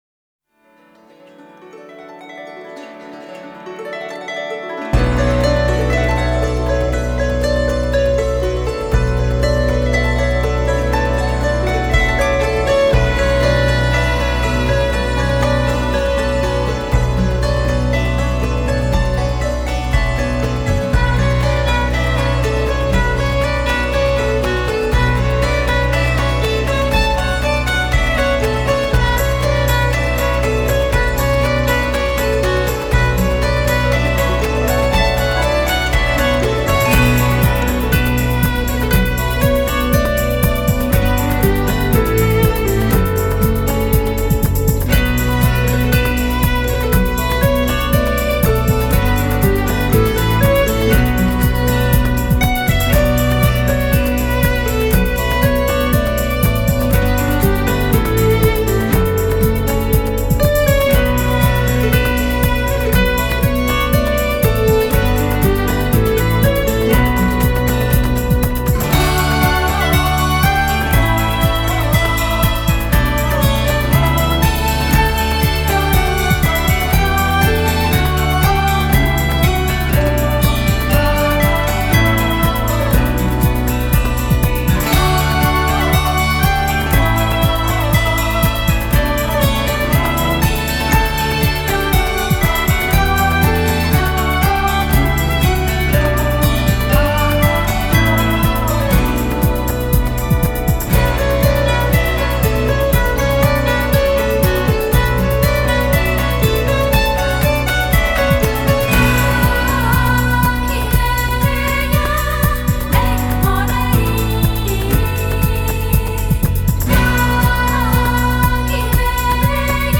音乐流派：新世纪音乐 (New Age) / 世界音乐 (World Music)
晶莹剔透、质感轻盈的竖琴，静谧悠长如风般轻诉的风笛，不用说，这就是爱尔兰音乐了